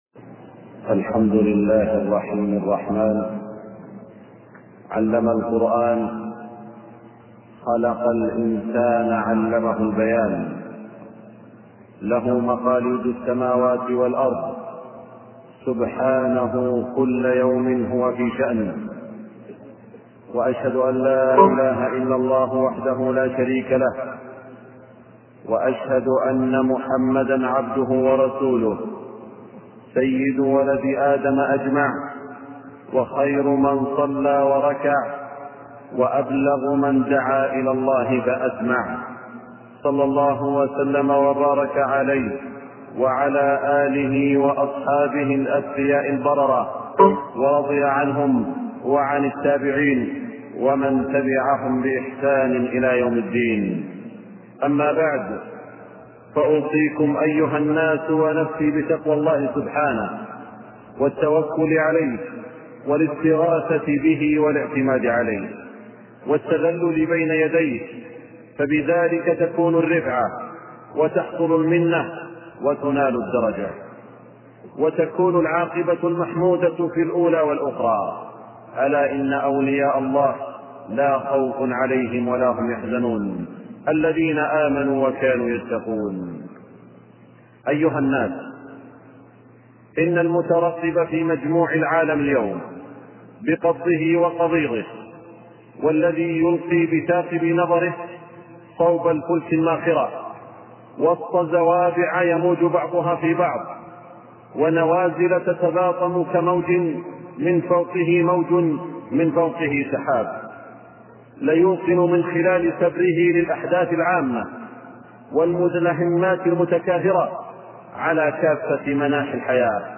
خطبة الحرم المكي 11 ذو القعدة 1422ه - طريق الإسلام
خطبة الحرم المكي 11 ذو القعدة 1422ه (صوت). التصنيف: خطب الجمعة